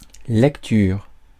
Ääntäminen
Synonyymit lisibilité Ääntäminen France: IPA: [lɛk.tyʁ] Haettu sana löytyi näillä lähdekielillä: ranska Käännös 1. четене {n} (čétene) Suku: f .